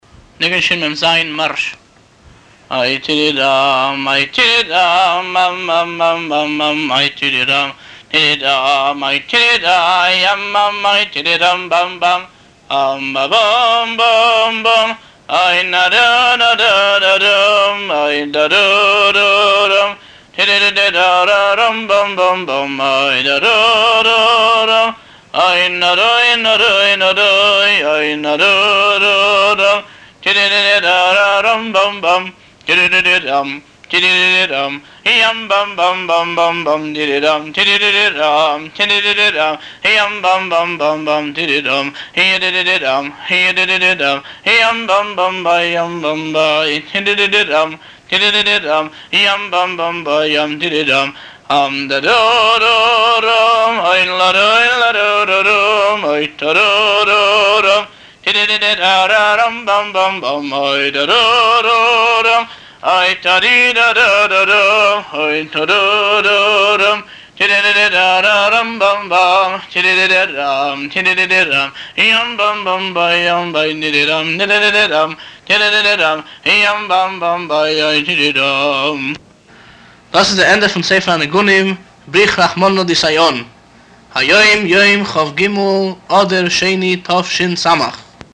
ניגון שמ"ז - מארש